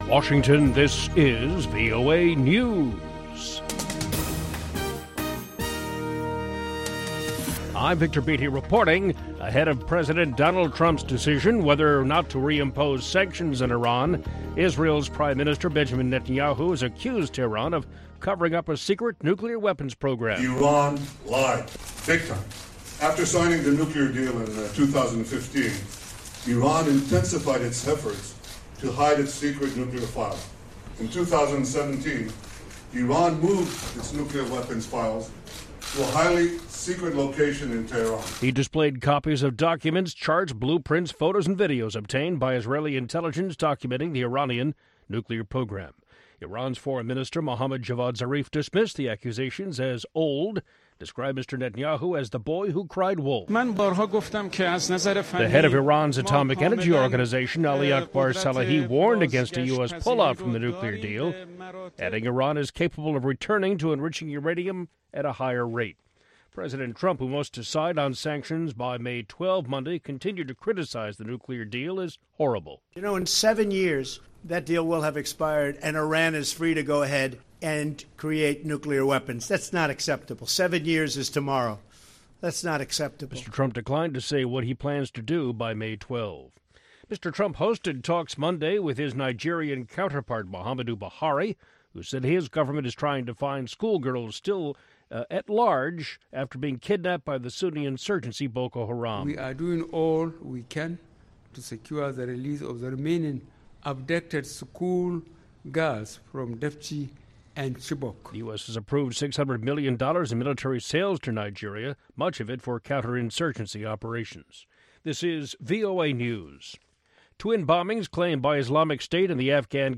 contemporary African music and conversation